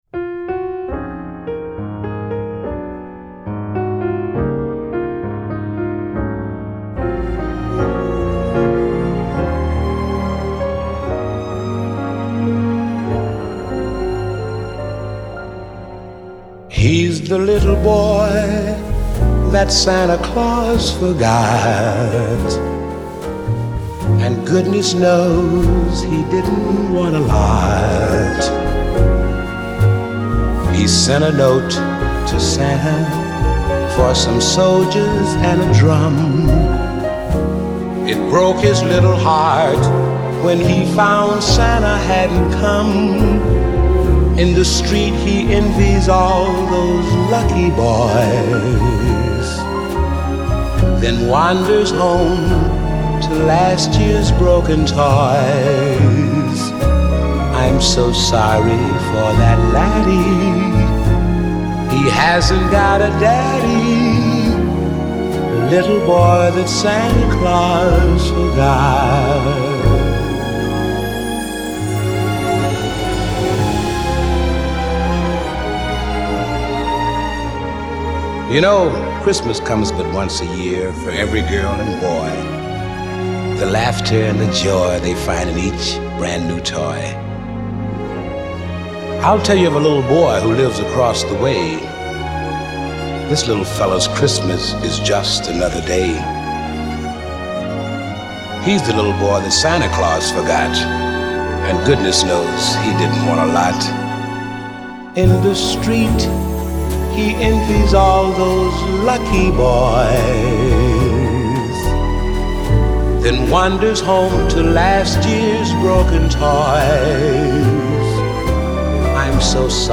Genre : Ambient, New Age